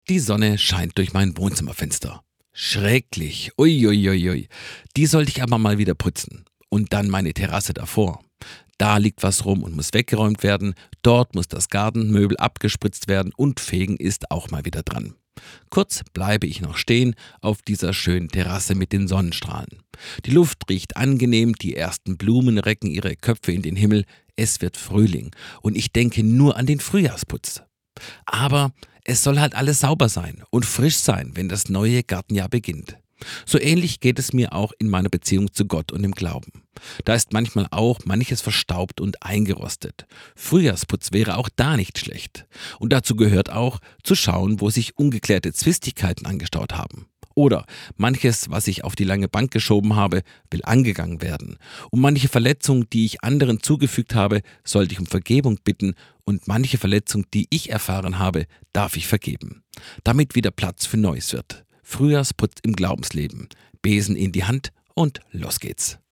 in andacht